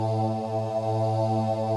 WAIL PAD 2.wav